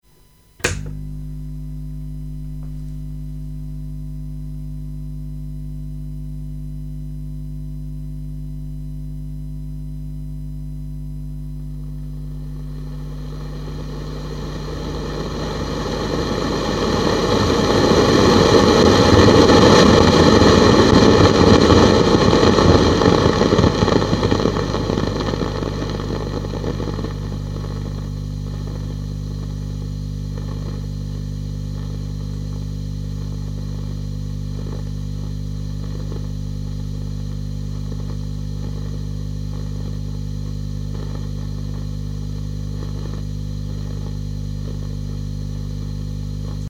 Depuis quelques jours, mon Vox me fait un gros bruit quelques secondes après l'allumage avant de disparaitre: